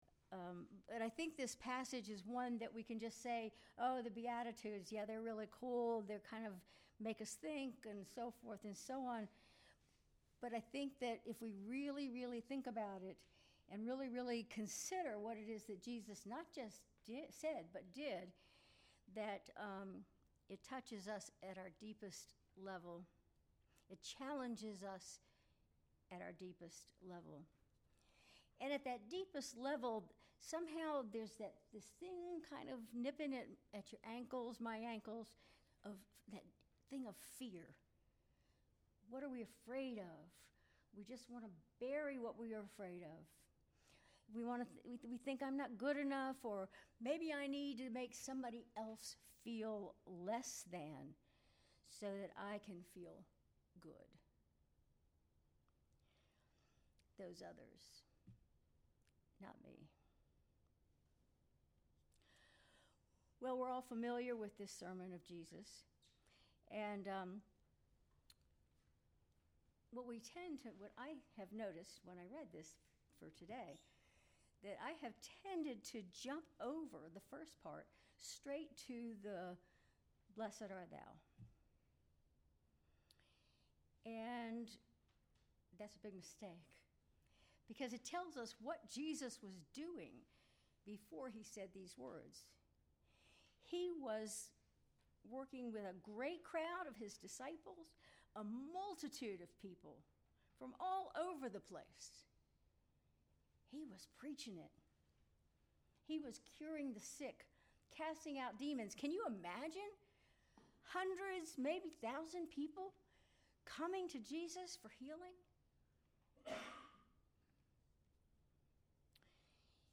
Sermon February 16, 2025